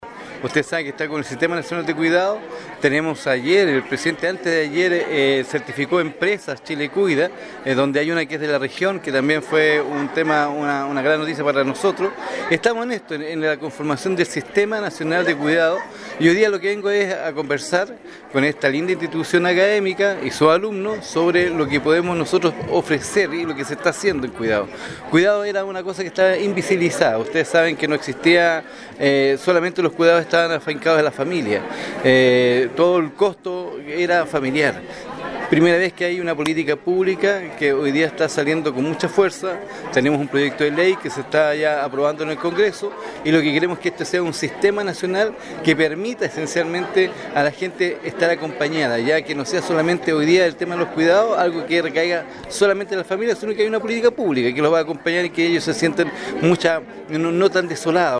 En el Instituto Profesional Santo Tomás Osorno se llevó a cabo ayer el conversatorio titulado “Chile Cuida: Construyendo un País que Protege”, en el marco de la Semana de Servicio Social. Esta significativa actividad tiene como objetivo promover acciones de corresponsabilidad social en el cuidado, contribuyendo así al desarrollo del país.